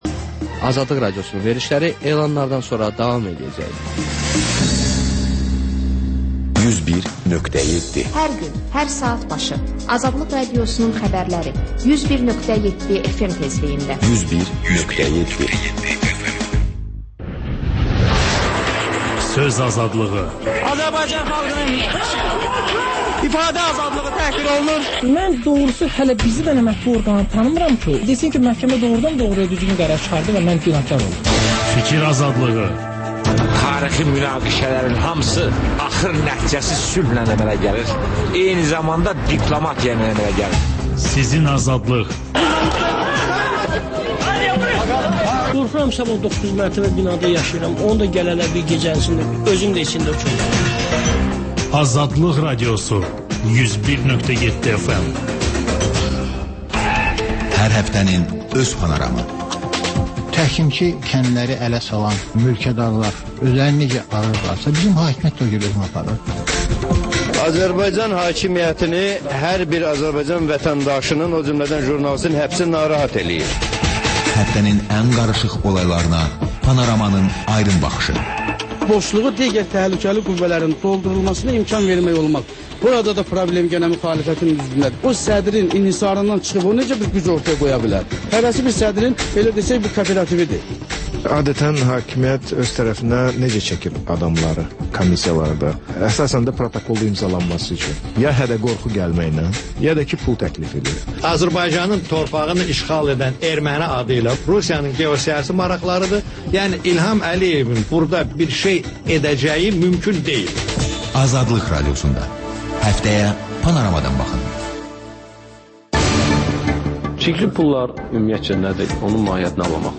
Xəbərlər, müsahibələr, hadisələrin müzakirəsi, təhlillər, sonda HƏMYERLİ rubrikası: Xaricdə yaşayan azərbaycanlılar haqda veriliş